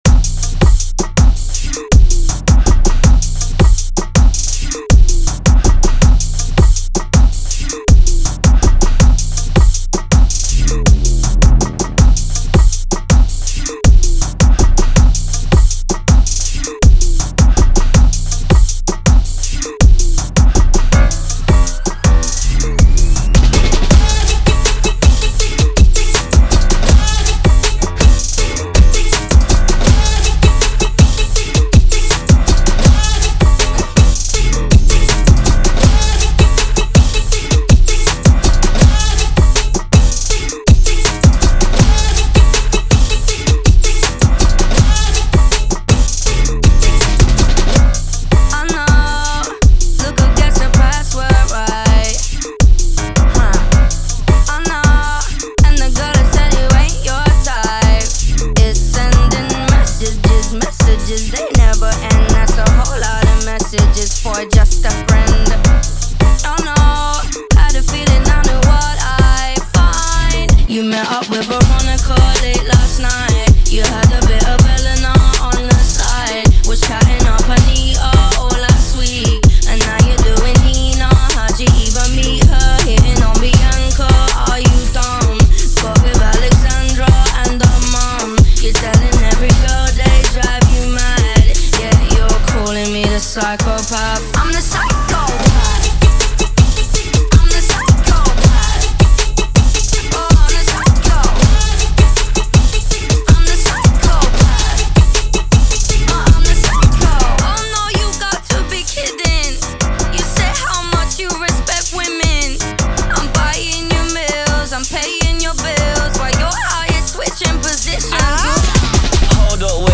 Genres: RE-DRUM , TOP40 Version: Clean BPM: 80.6 Time